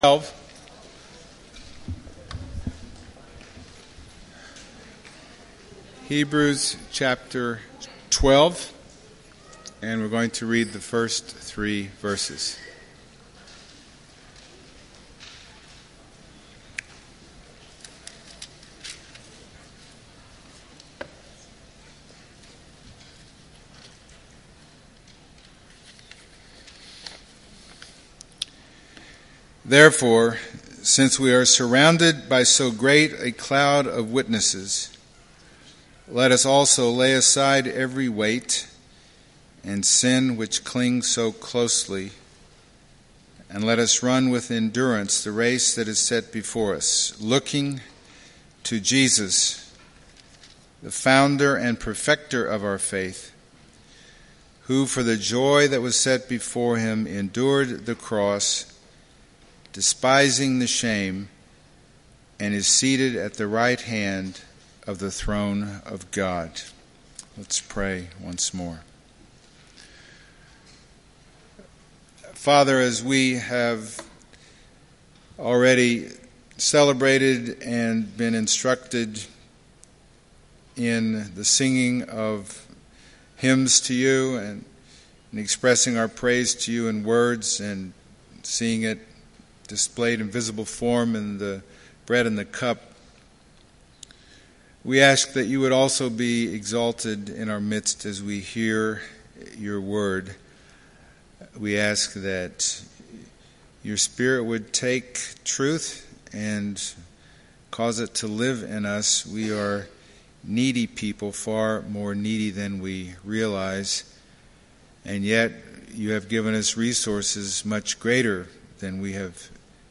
Passage: Hebrews 12:1-3 Service Type: Sunday Morning